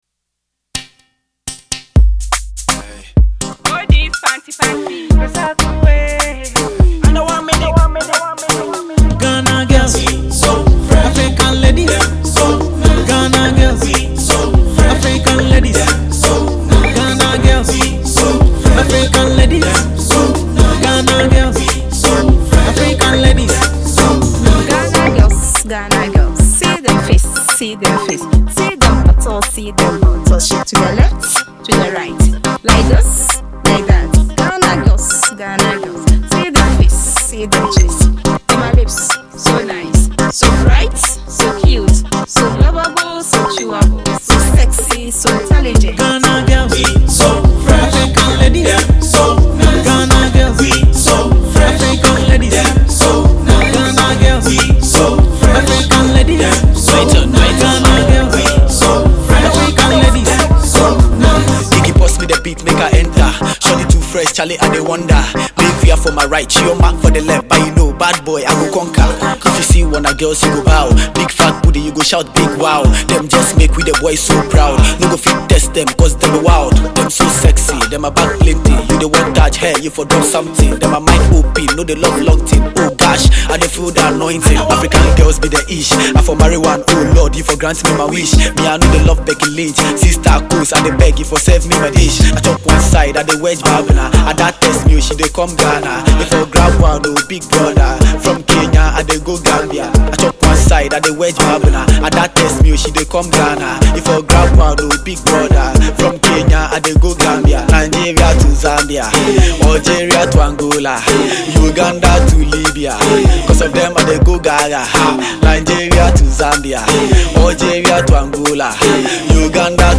Ghana Afrobeat MP3